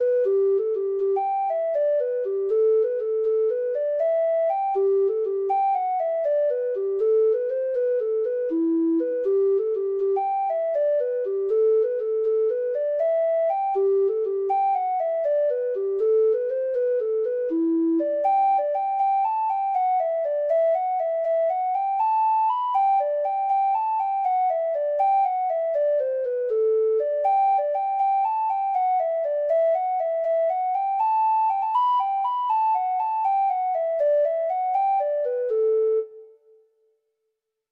Free Sheet music for Treble Clef Instrument
Irish Slip Jigs